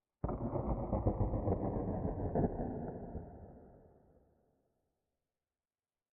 Minecraft Version Minecraft Version latest Latest Release | Latest Snapshot latest / assets / minecraft / sounds / ambient / nether / nether_wastes / addition6.ogg Compare With Compare With Latest Release | Latest Snapshot